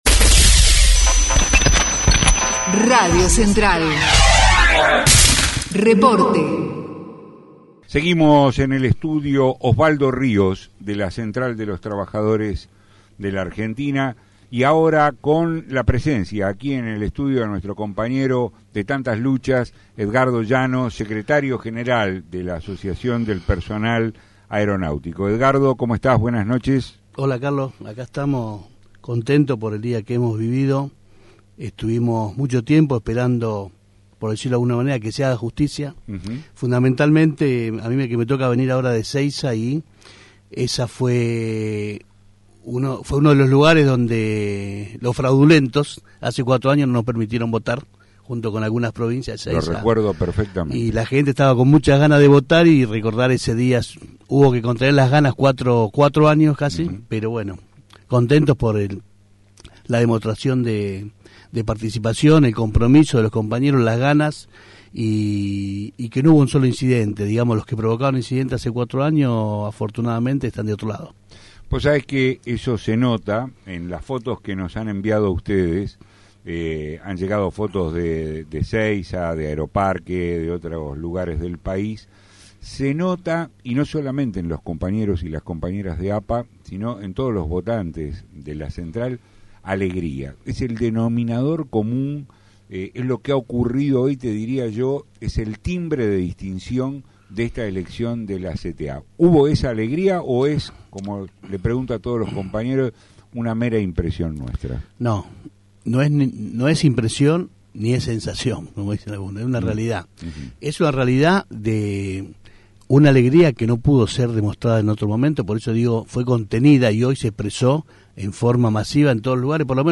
entrevista) RADIO CENTRAL